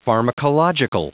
Prononciation du mot pharmacological en anglais (fichier audio)
Prononciation du mot : pharmacological